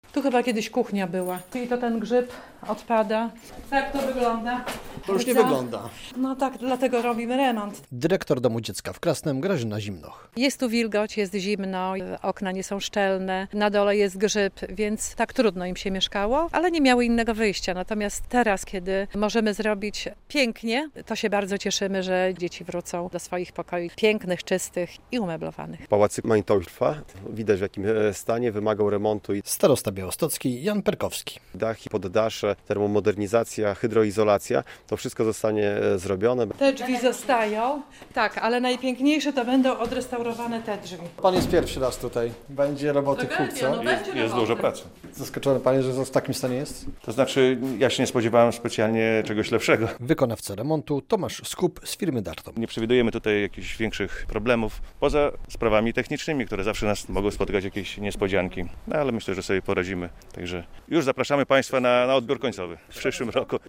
Polskie Radio Białystok